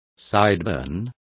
Complete with pronunciation of the translation of sideburn.